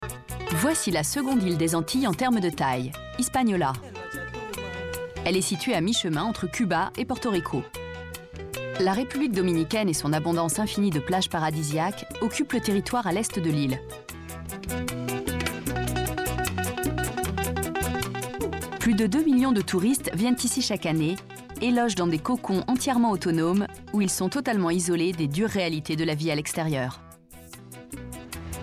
Narration Voyage voix dynamique voix informative Narration Catégories / Types de Voix Extrait : Votre navigateur ne gère pas l'élément video .